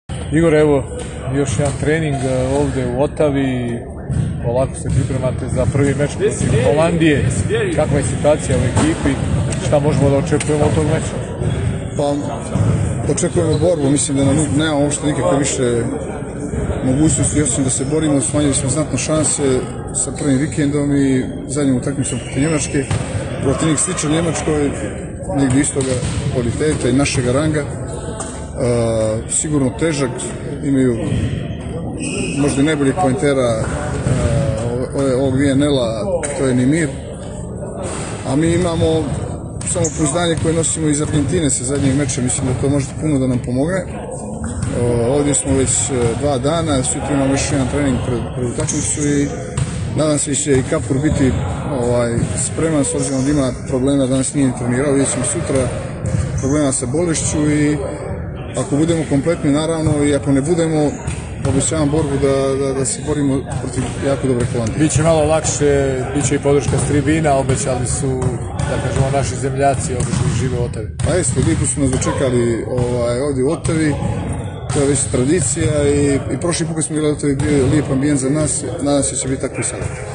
Izjava Igora Kolakovića